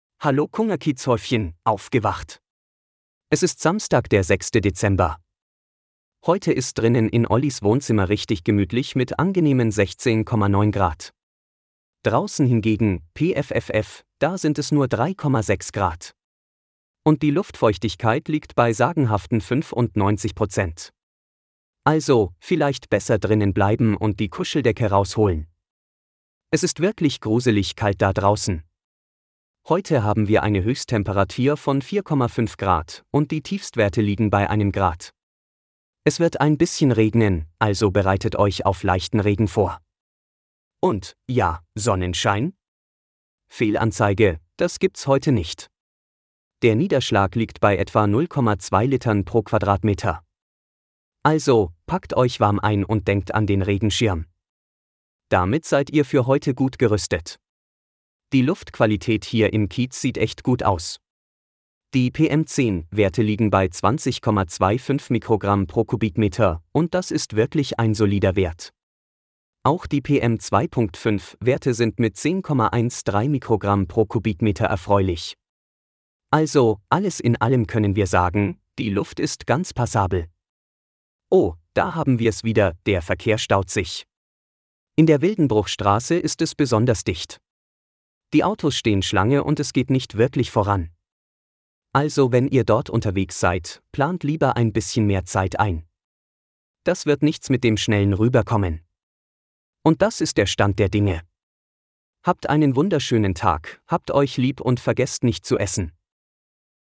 Automatisierter Podcast mit aktuellen Wetter-, Verkehrs- und Geburtstagsinfos.